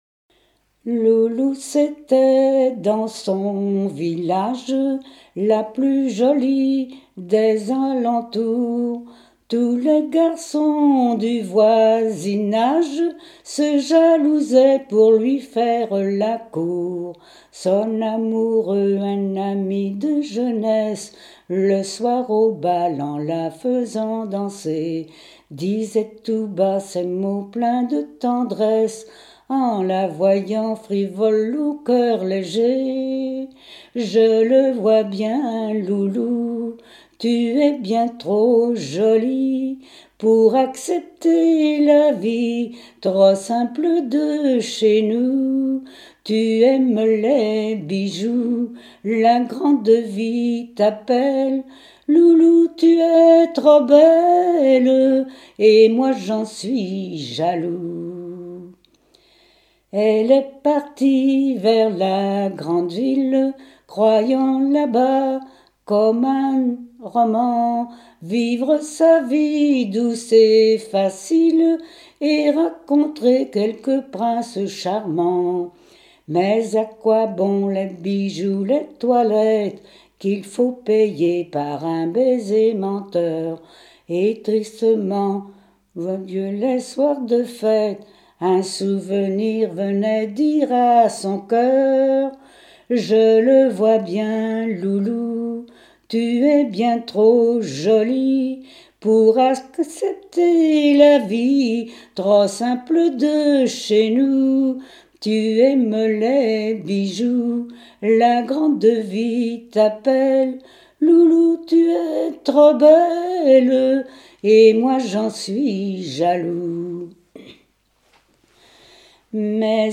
Genre strophique
Témoignages et chansons
Pièce musicale inédite